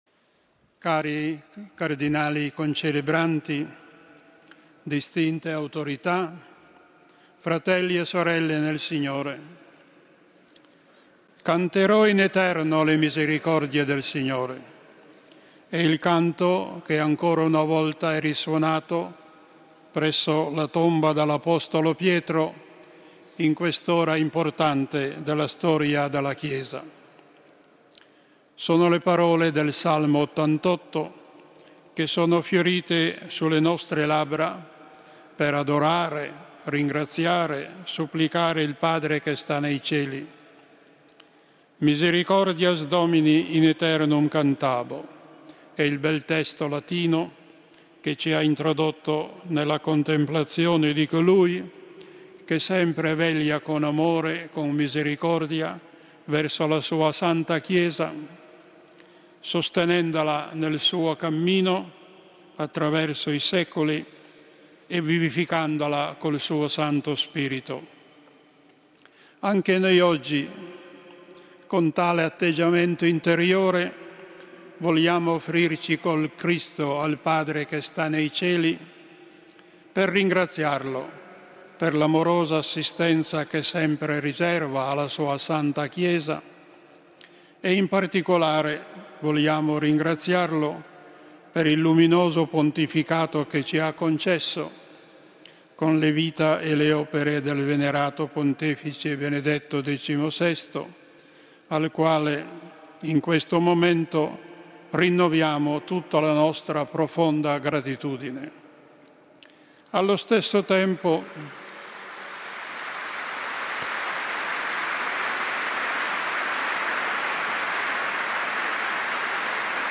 Nell'omelia del card. Angelo Sodano , Decano del Collegio Cardinalizio, in occasione della Messa pro eligendo Pontifice, l'implorazione al Signore di concedere alla Chiesa un altro Buon Pastore. Rinnovata anche la gratitudine nei confronti di Benedetto XVI per il suo luminoso Pontificato.